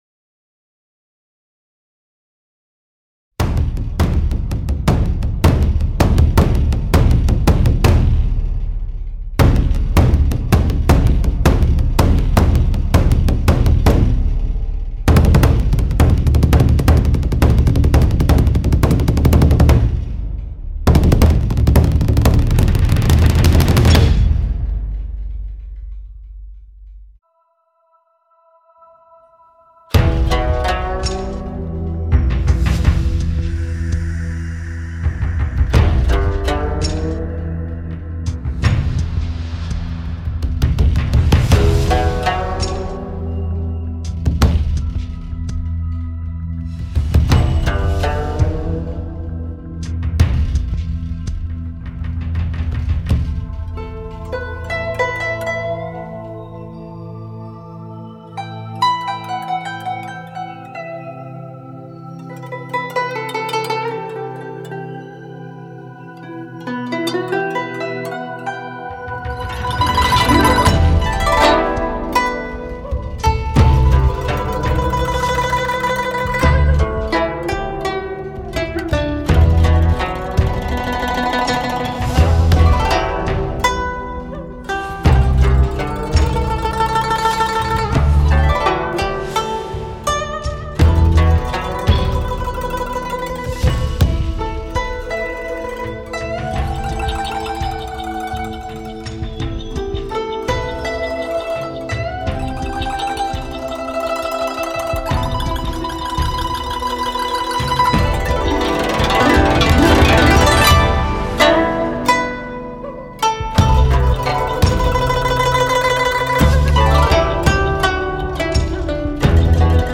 不论是鼓点的力量感、空气感还是弦外的音韵，一开场便震慑全场。
古筝一出，如篆如刻，入木三分。